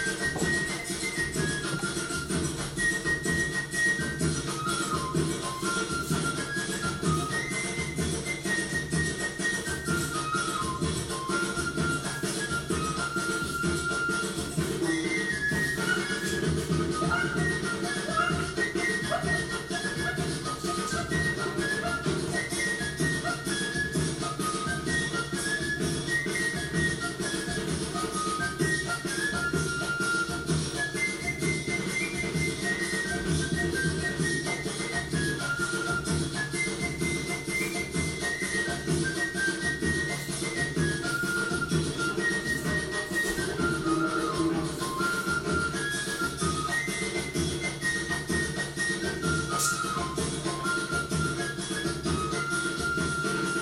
carnival-Peru-Good-music-for-doco.-1.m4a